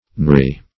knarry - definition of knarry - synonyms, pronunciation, spelling from Free Dictionary Search Result for " knarry" : The Collaborative International Dictionary of English v.0.48: Knarry \Knar"ry\ (n[aum]r"r[y^]), a. Knotty; gnarled.